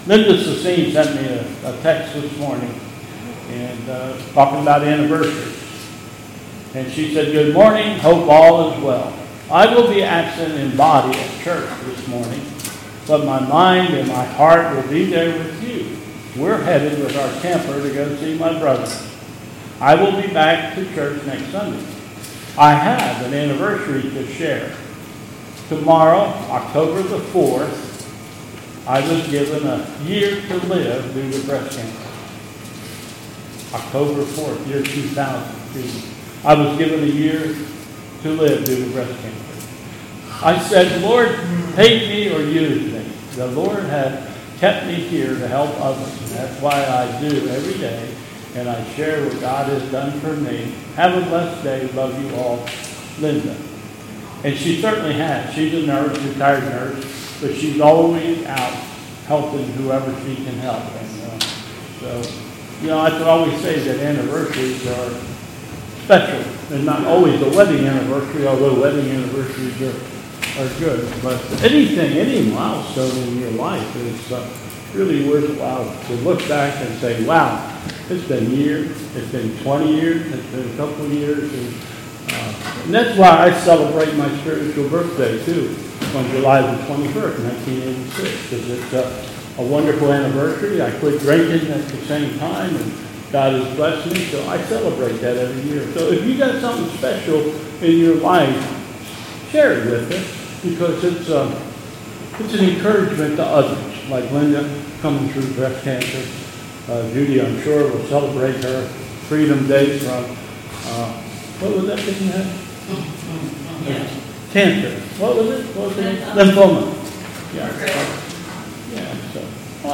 2021 Bethel Covid Time Service
Affirmation of faith and Doxology